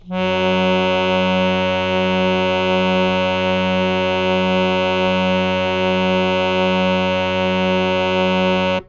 harmonium
Fs2.wav